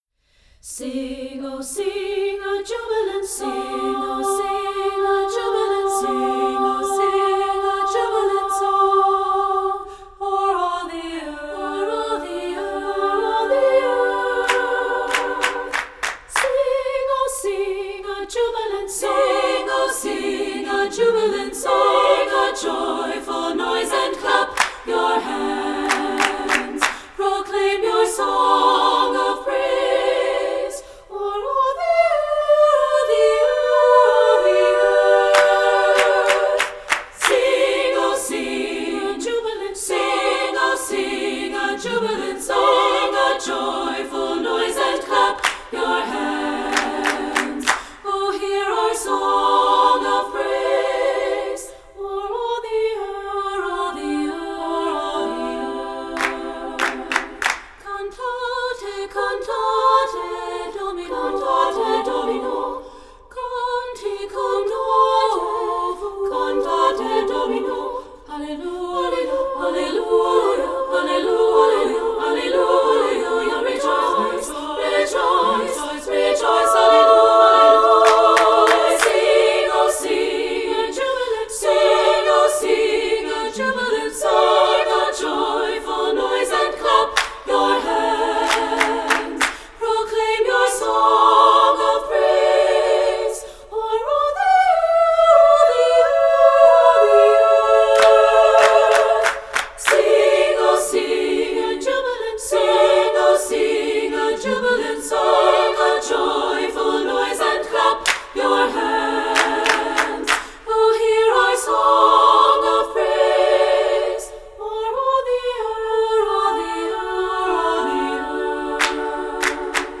Voicing: TBB a cappella